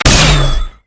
fire_gun2_rank2.wav